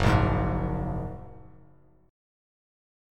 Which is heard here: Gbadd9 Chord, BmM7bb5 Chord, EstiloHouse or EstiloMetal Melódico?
Gbadd9 Chord